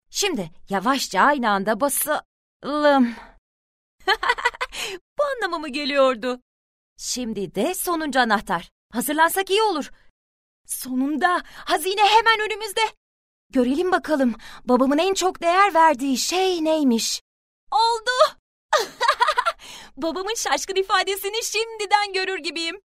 游戏角色-爽朗豪放